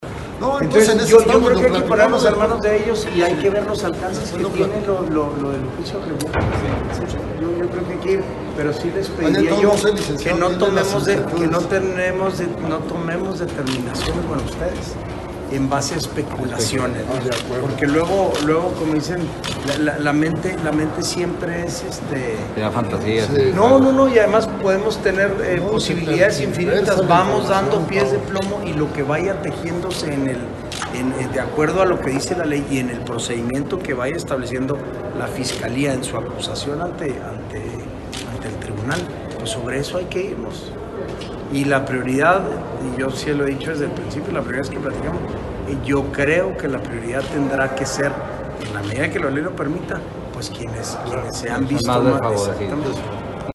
AUDIO: SANTIAGO DE LA PEÑA GRAJEDA, TITULAR DE LA SECRETARÍA GENERAL DE GOBIERNO (SGG)